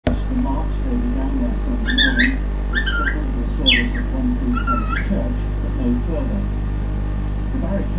"Cheeky Cheek" our cockatiel
Hear her song here!